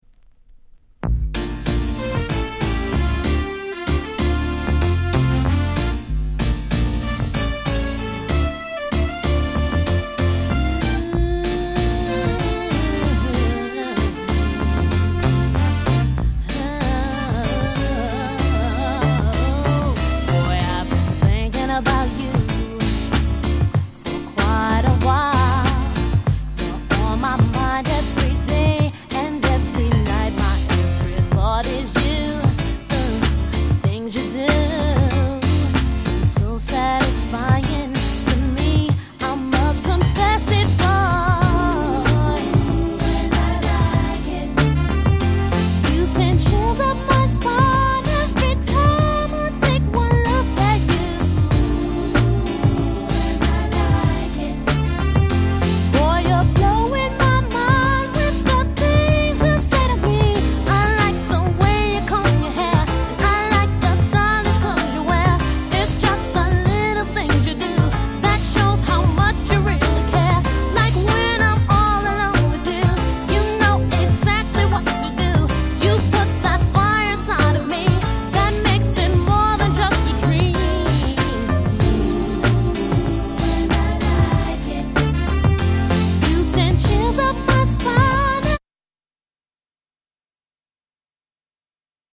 問答無用の90's R&B Classic !!